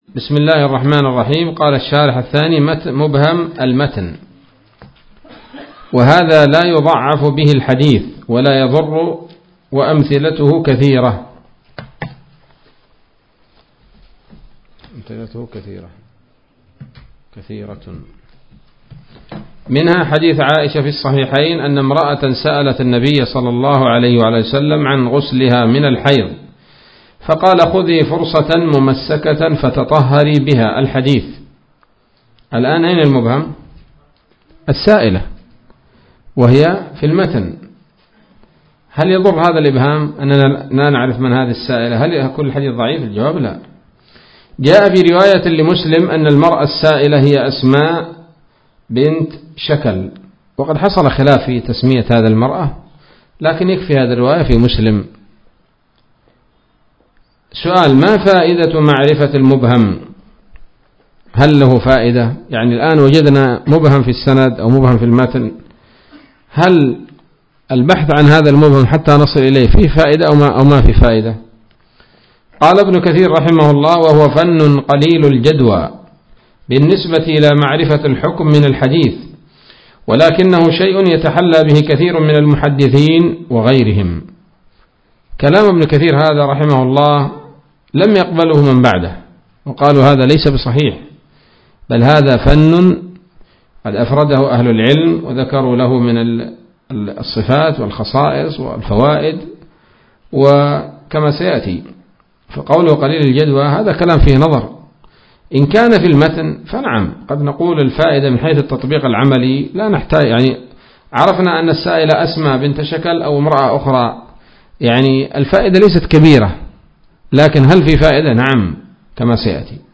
الدرس التاسع عشر من الفتوحات القيومية في شرح البيقونية [1444هـ]